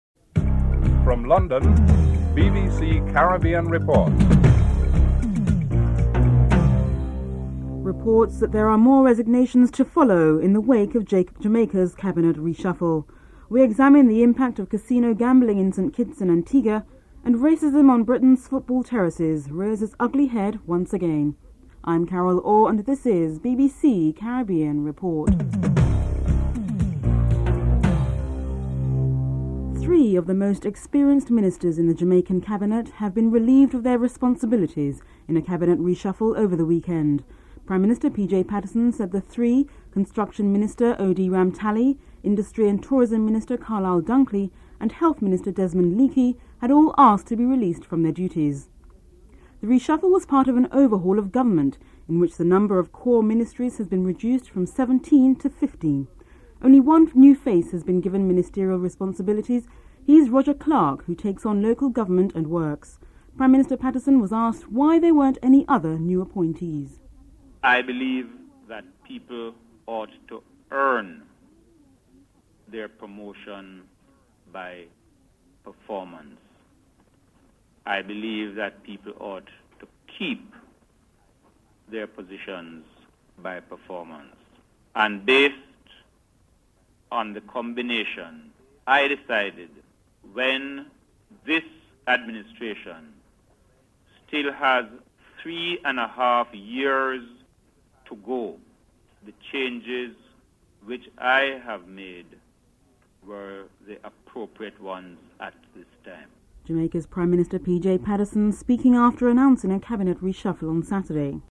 2. Report on Jamaica's Cabinet reshuffle (00:29-01:07)